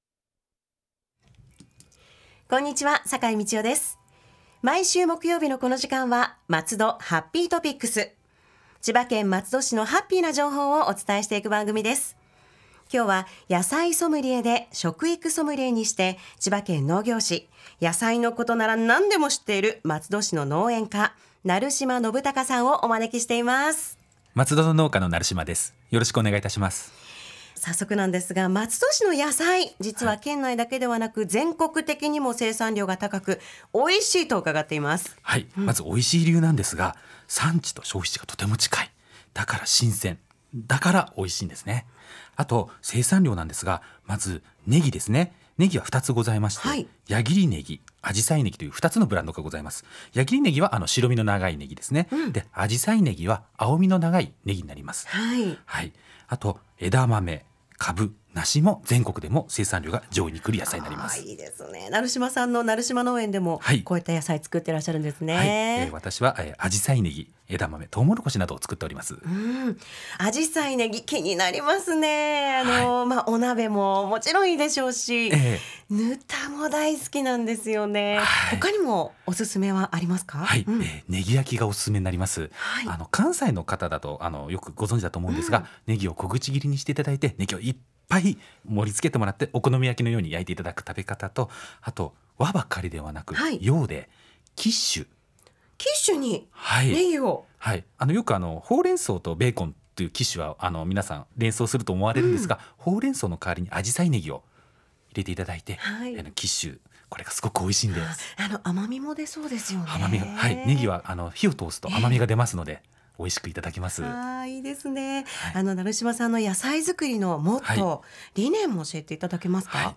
※著作権の関係により、バックミュージックなどの音楽を削除しています。